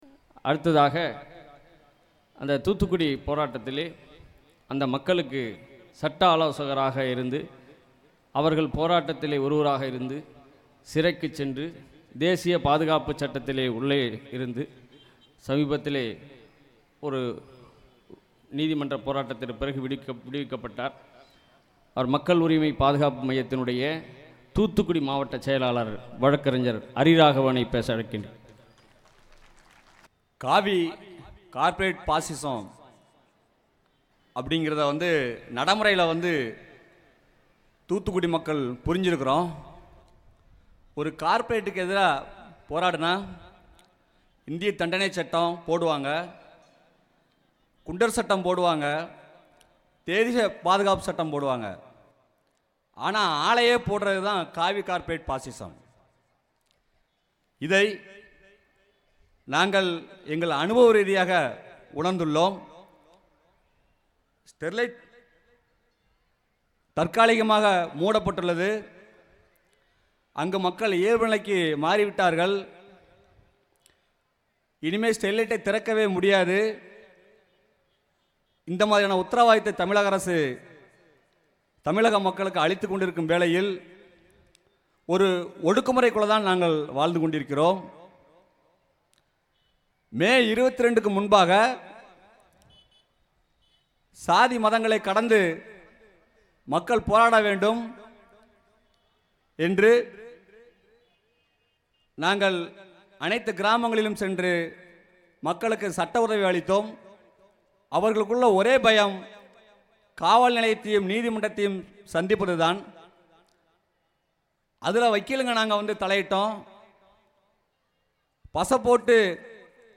கடந்த பிப்ரவரி 23, 2019 அன்று திருச்சியில் மக்கள் அதிகாரம் அமைப்பின் சார்பில் நடைபெற்ற ” கார்ப்பரேட் – காவி பாசிசம் ! எதிர்த்து நில் ! ” மாநாட்டில்
நேருரை